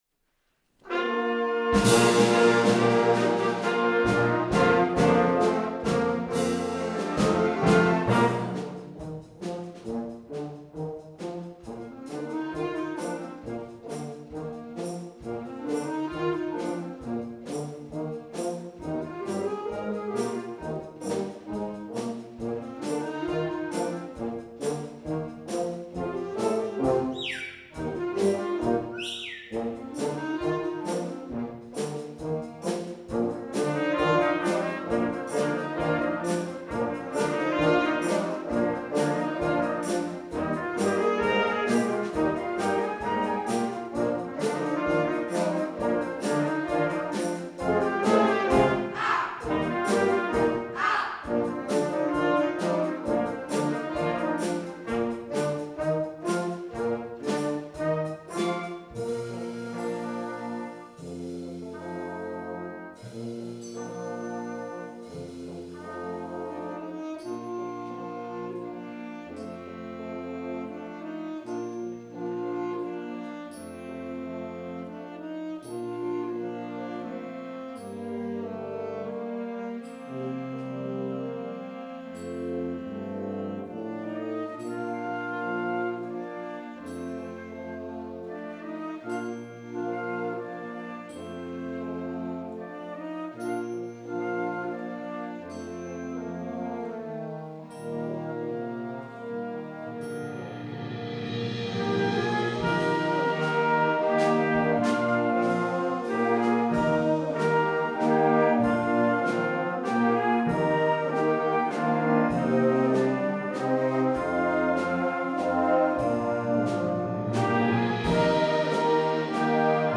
Schulorchester
Konzertwertung 2017, Riedau, Pramtalsaal